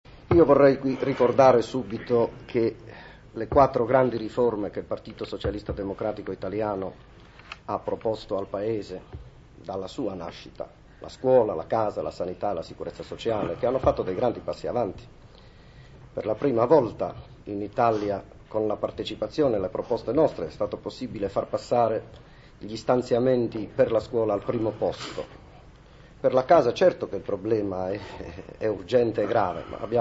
Intervento di Mario Tanassi